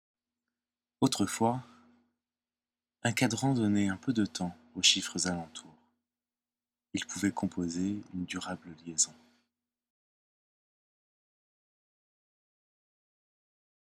Poésie sonore